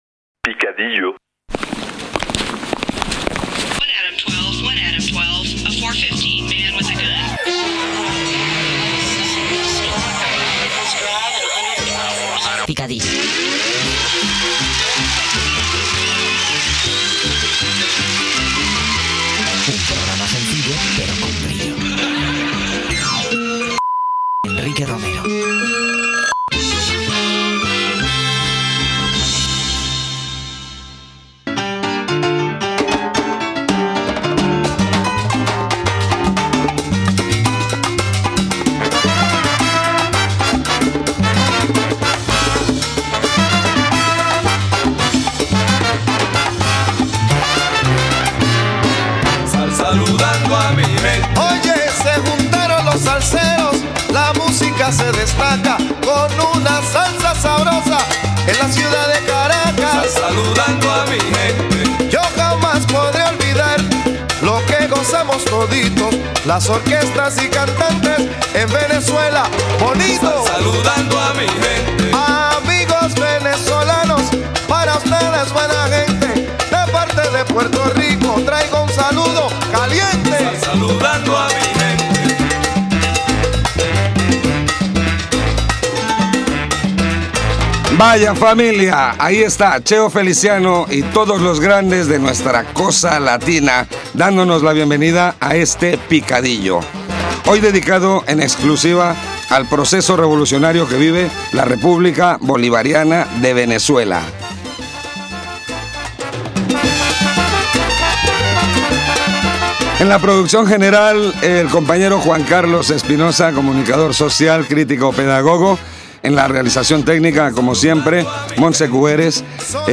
Careta del programa, tema musical, salutació, equip
Gènere radiofònic Musical